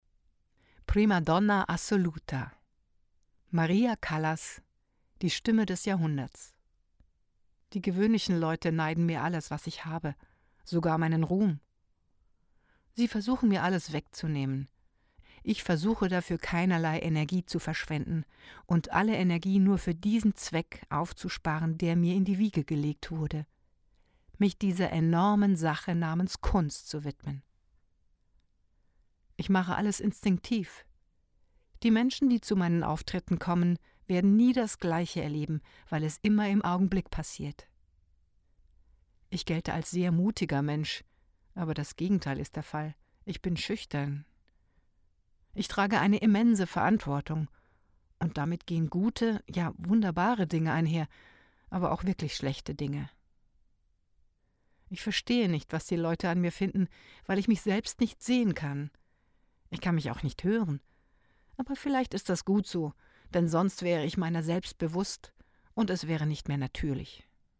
Musikdoku
Voiceover